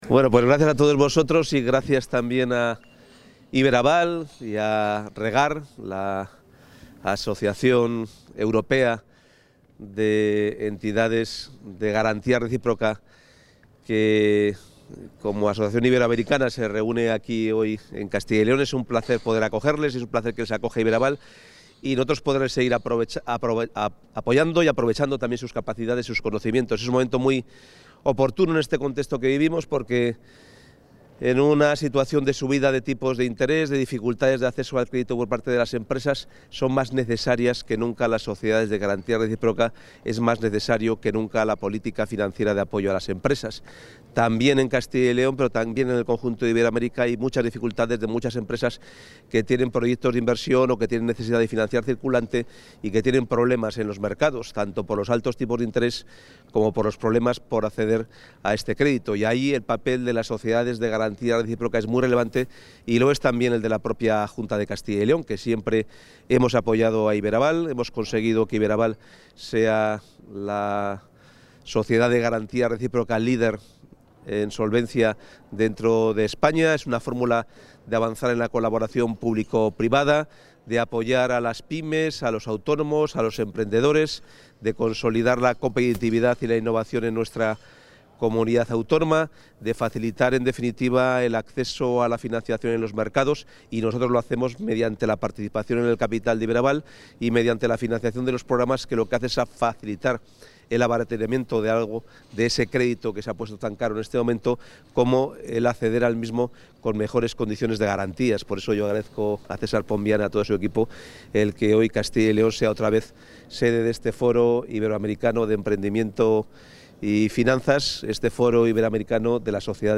Material audiovisual de la intervención del consejero de Economía y Hacienda en el XXVI Foro Iberoamericano de Garantías
Intervención del consejero.
El consejero de Economía y Hacienda y portavoz, Carlos Fernández Carriedo, ha intervenido hoy en el XXVI Foro Iberoamericano de Garantías, organizado por Iberaval en el Centro Cultural Miguel Delibes de Valladolid.